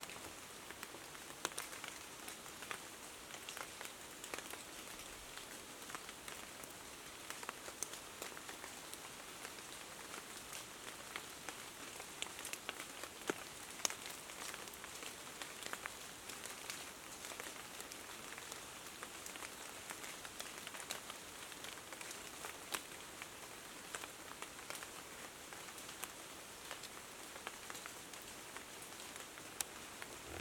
取材時は小雨で、気温は13℃前後とひんやりというよりも寒い一日でした。
雨の日の森の中は葉っぱや自分の雨具にあたってぱらぱら、ぽとぽと、と楽しい音が聞こえてきます。
面白いのがあたる物によって音の高さやリズムが違うこと。
amenooto.mp3